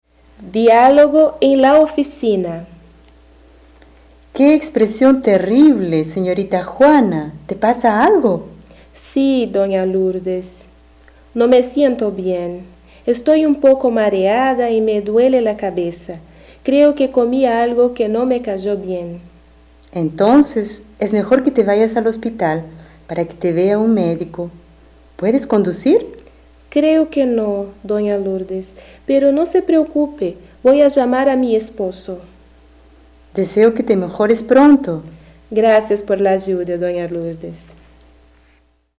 Title: Diálogo X: Na oficina
Description: Áudio do livro didático Língua Espanhola I, de 2008. Diálogo com palavras referentes as partes do corpo.